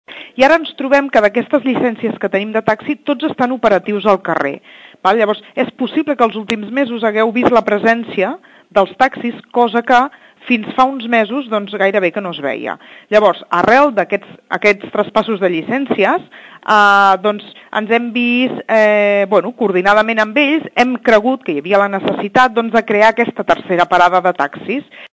Sílvia Català, regidora de senyalització explica perquè han creat aquesta parada de taxis.